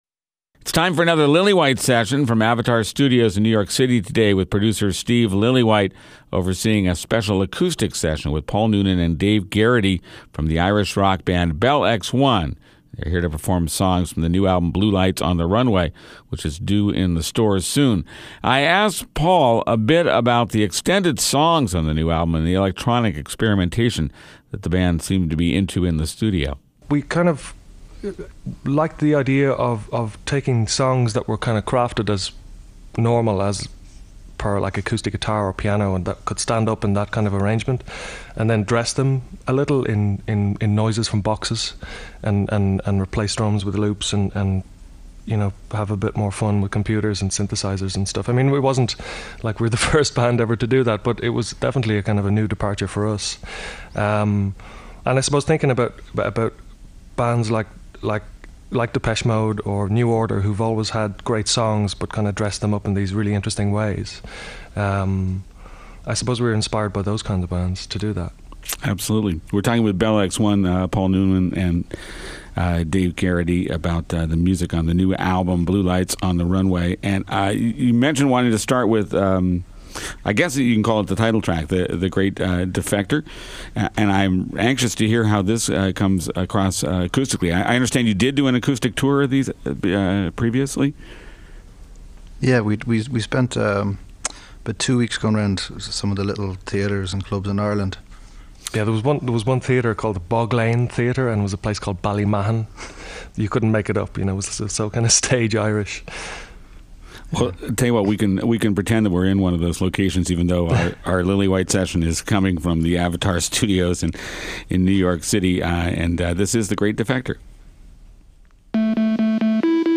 rock band
catchy pop and electronica